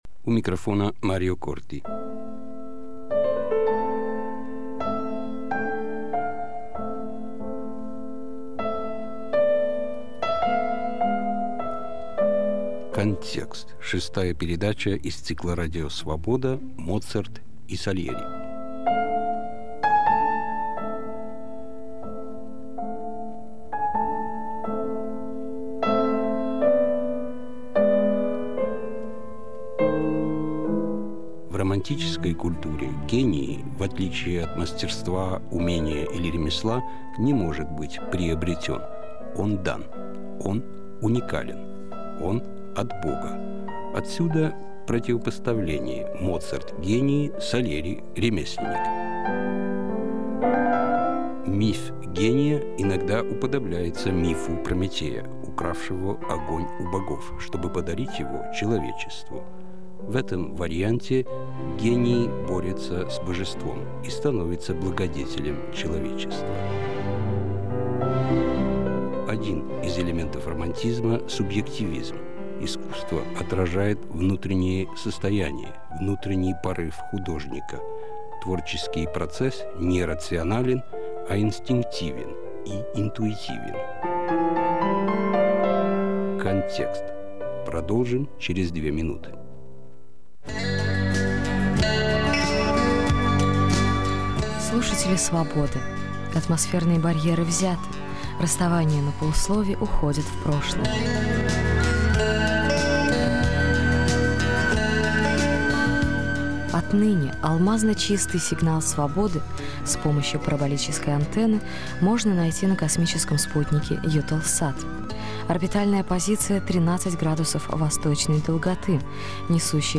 Моцарт и Сальери. Историко-музыкальный цикл из девяти передач, 1997 год.